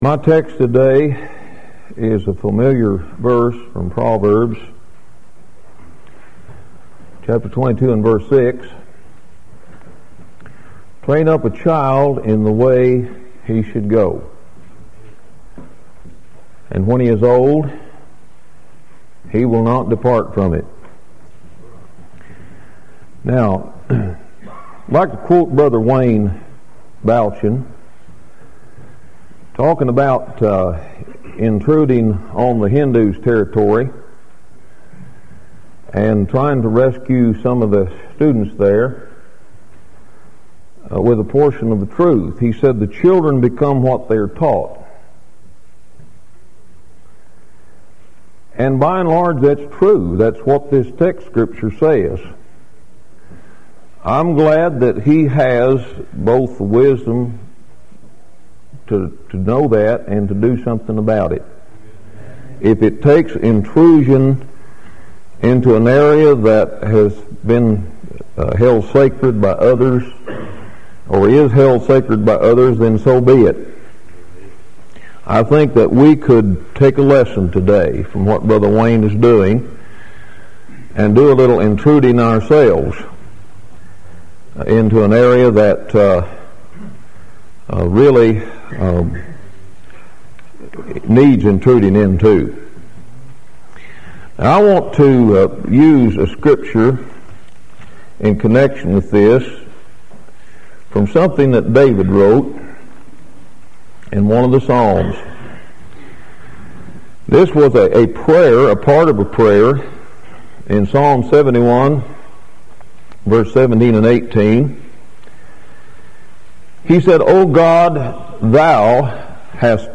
Lesson 2 from the 1999 session of the Old Union Ministers School.